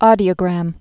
dē-ə-grăm)